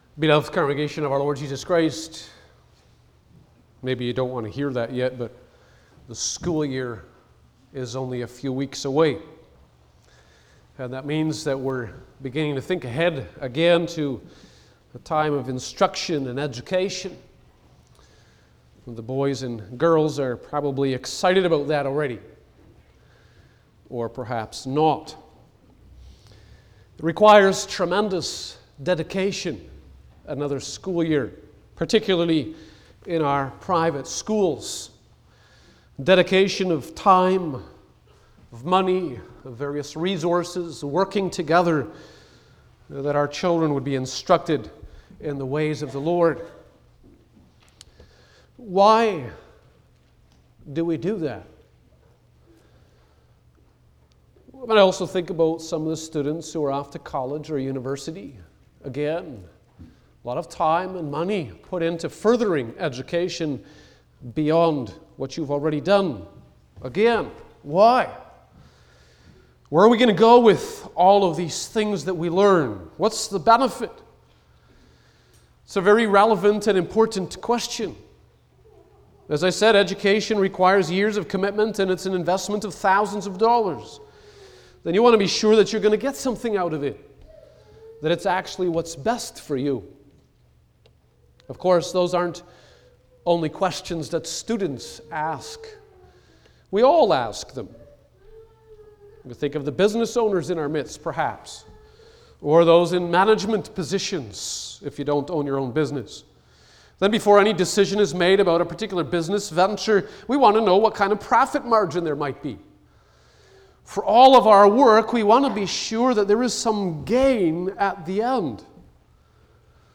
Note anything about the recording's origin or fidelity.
Passage: Lord’s Day 23 Service Type: Sunday afternoon